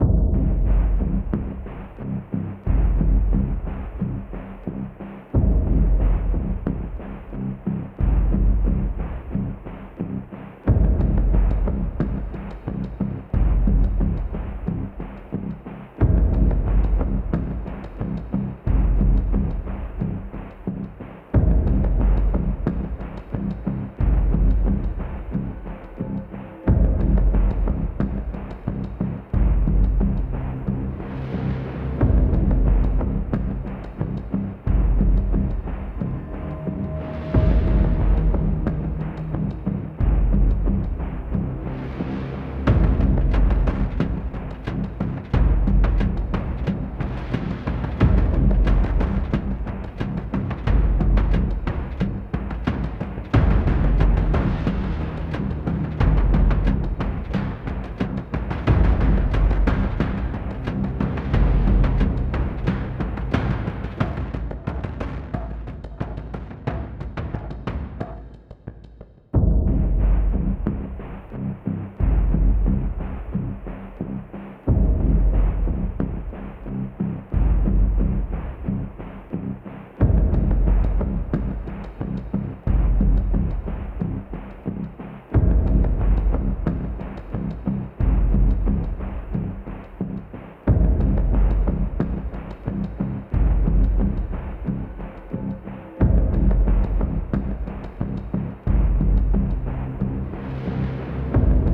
tension driving